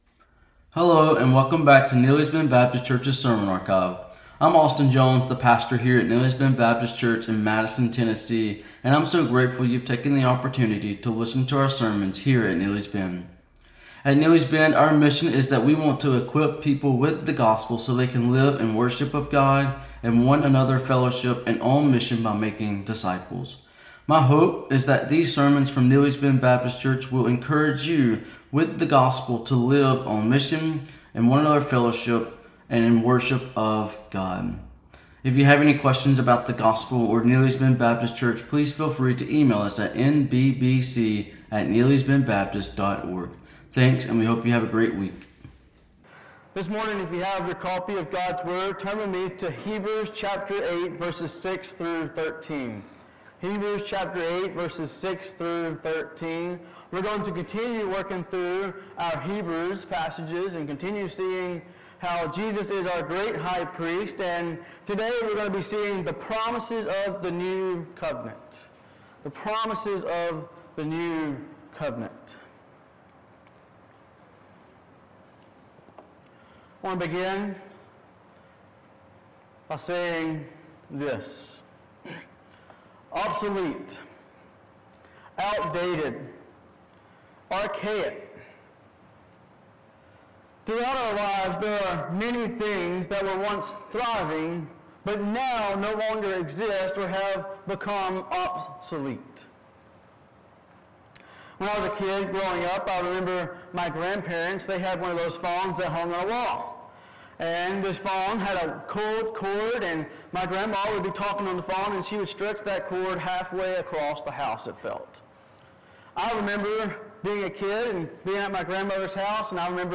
What makes the new covenant better? This question is answered in this sermon from Hebrews 8:6-13 as the author presents three promises that prove it to be better than the old covenant.